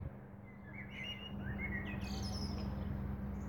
Første del kan faktisk minne litt om pirol, mens den siste halvdelen har en slutt som gir sangen et trosteaktig preg.
fugl.m4a